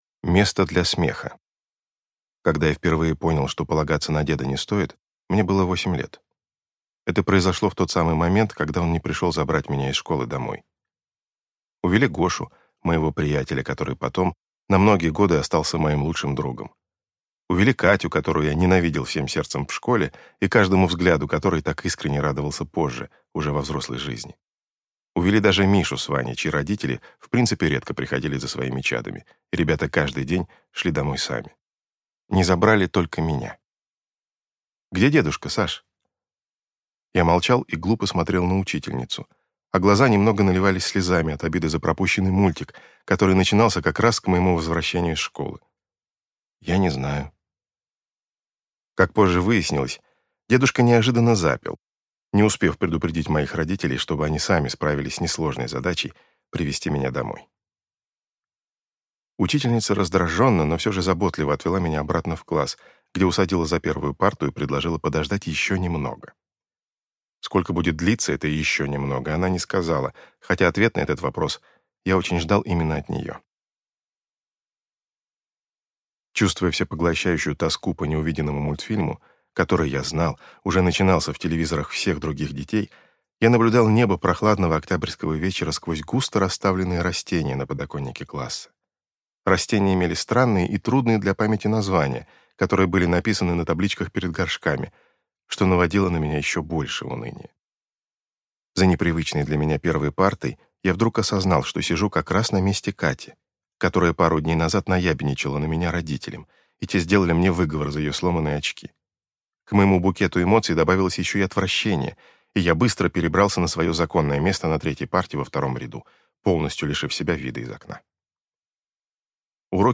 Аудиокнига Джойфул | Библиотека аудиокниг